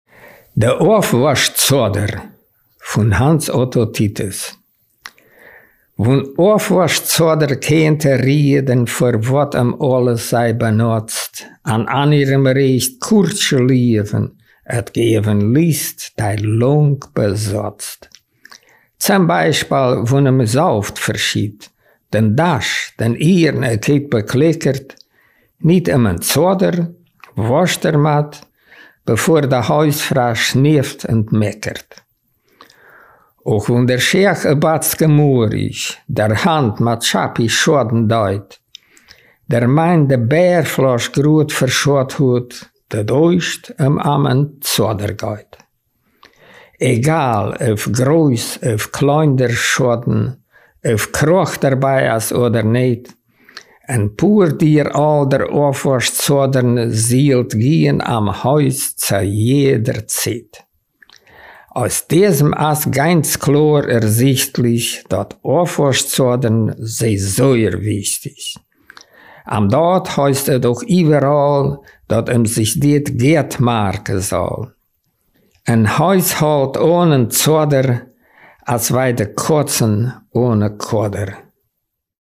Ortsmundart: Heldsdorf